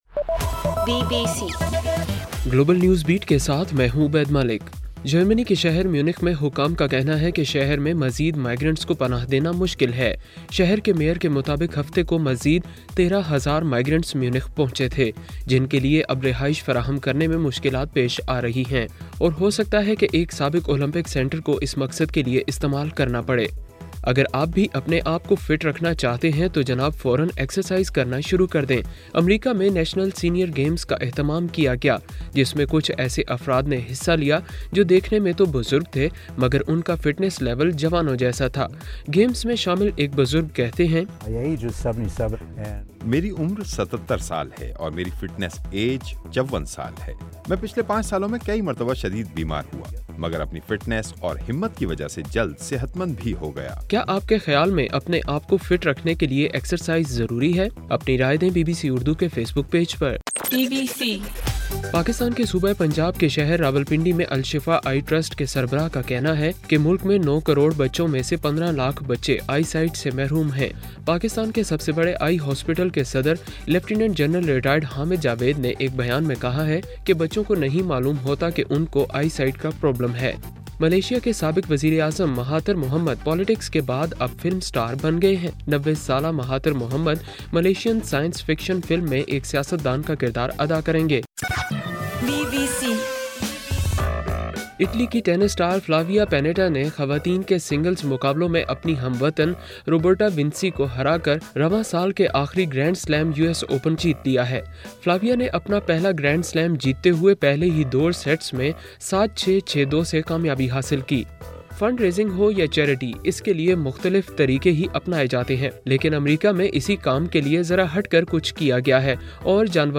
ستمبر 13: رات 8 بجے کا گلوبل نیوز بیٹ بُلیٹن